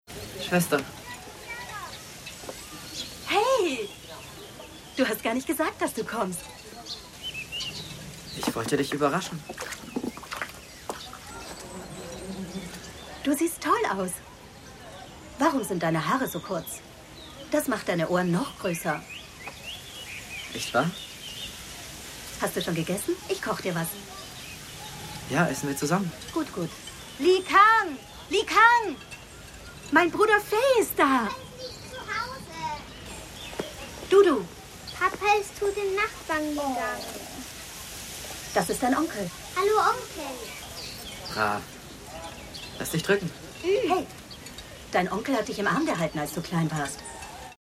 Hörproben der Sprecherin für Österreich & Deutschland, Moderatorin, Schauspielerin, deutsche Synchronsprecherin, deutsche Synchronstimme
Synchronsprecherin/Spielfilm Moneyboys Szene 3 DE/mp3